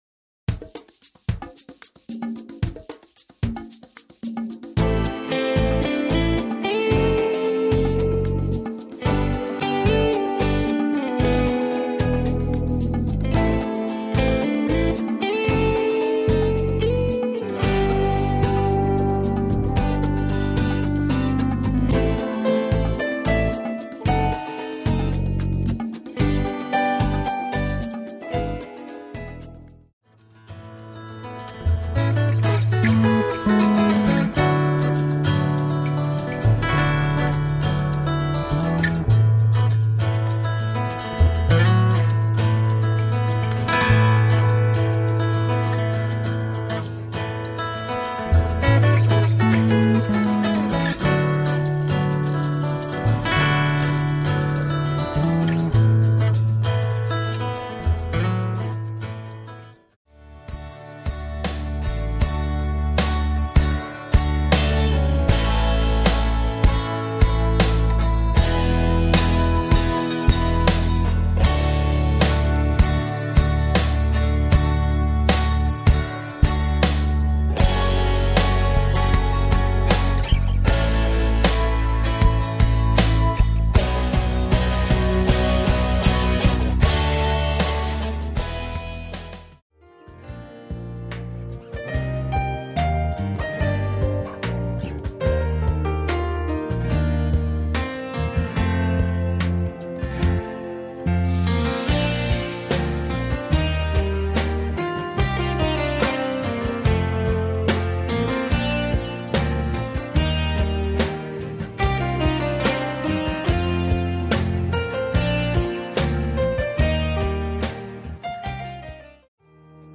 styl - chillout/ballads/jazz/pop